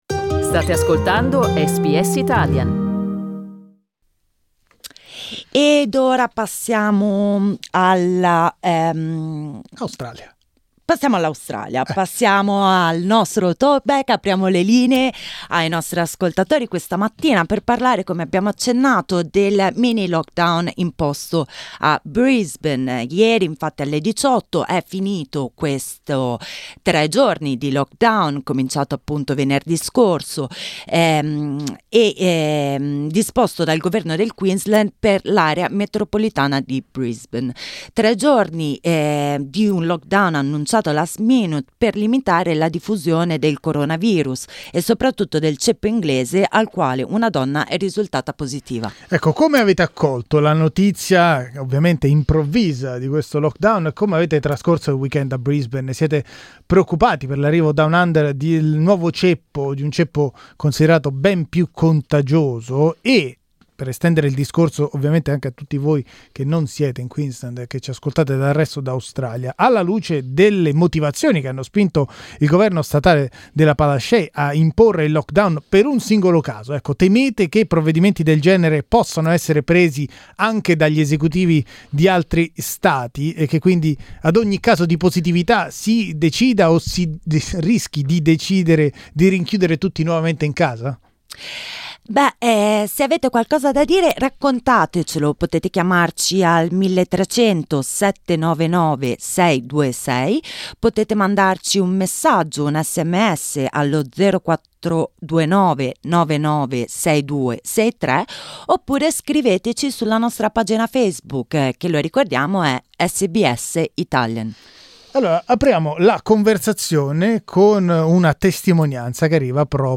Dopo il lockdown durato un weekend nella capitale del Queensland, questa mattina abbiamo ascoltato le testimonianze di alcuni residenti italiani.